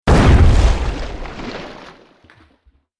دانلود آهنگ آب 29 از افکت صوتی طبیعت و محیط
دانلود صدای آب 29 از ساعد نیوز با لینک مستقیم و کیفیت بالا
جلوه های صوتی